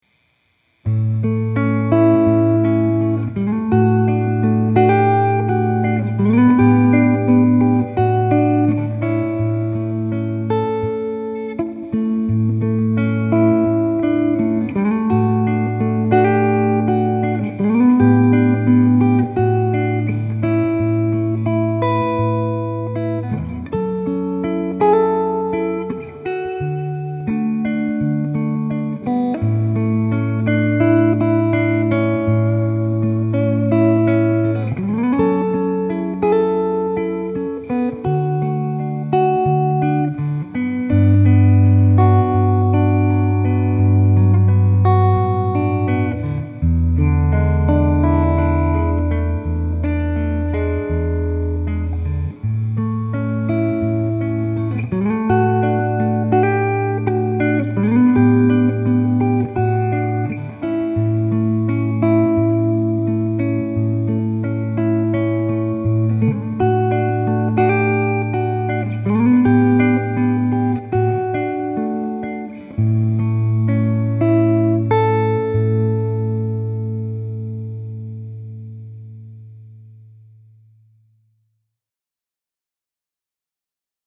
Neck pickup, clean
I love the haunting character of the neck pickup on and ES-335. The wonderful thing about this pickup is that it produces a very deep tone, without sounding like an acoustic. Adding a little reverb “grease” only accentuates the haunting effect.
For the clean clips, I used a ’65 Twin Reverb model, and for the crunchy clip, I used a ’59 Bassman.
335_clean_neck.mp3